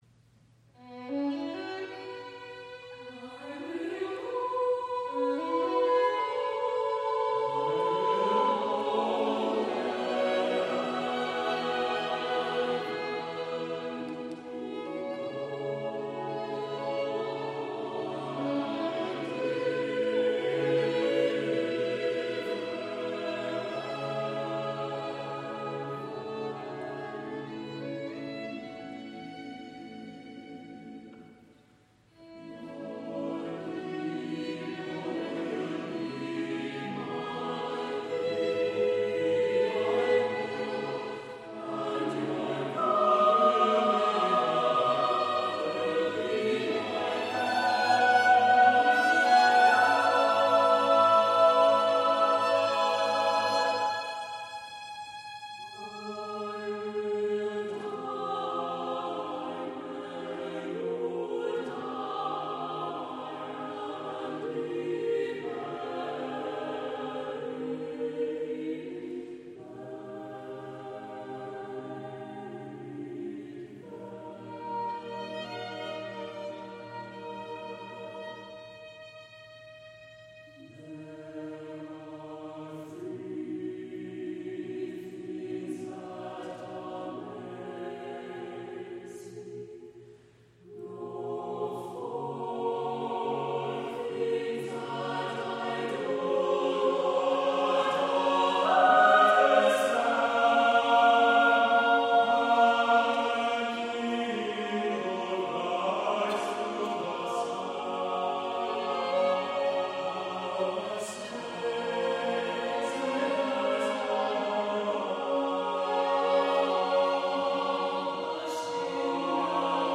SATB a cappella chorus with violin